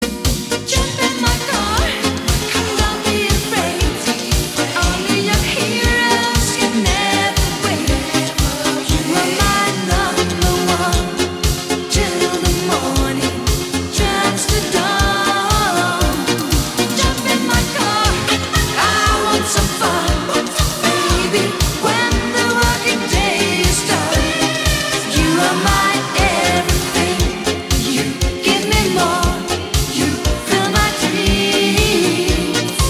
жанр, категория рингтона ЖАНР: РИНГТОНЫ 80е-90е